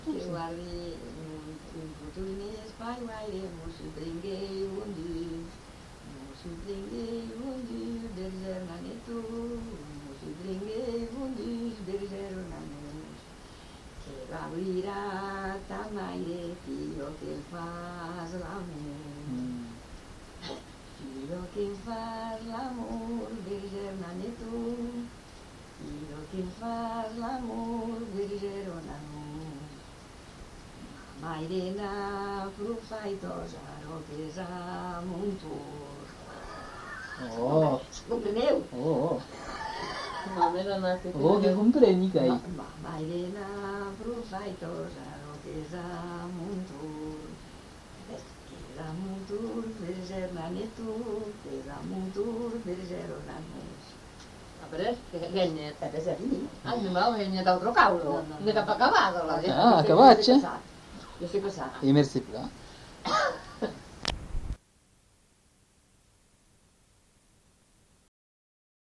Lieu : Ayet (lieu-dit)
Genre : chant
Effectif : 1
Type de voix : voix de femme
Production du son : chanté